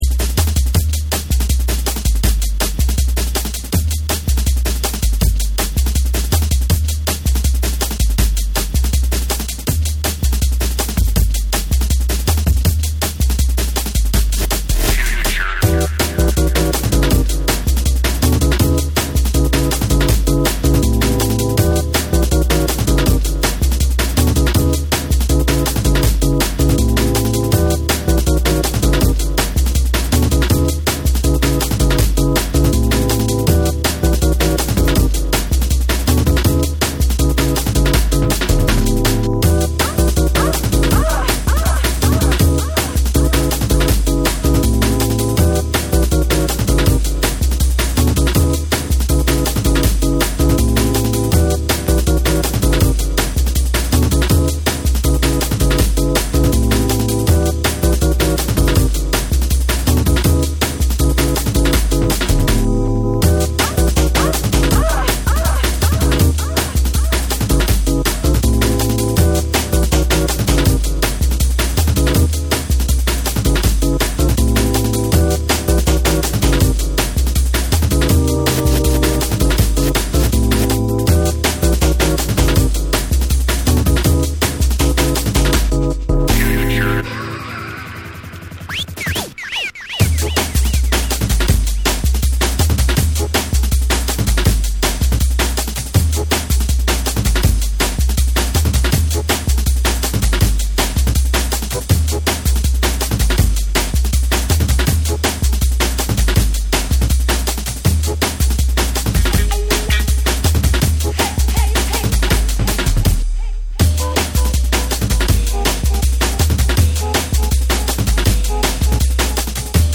JUNGLE & DRUM'N BASS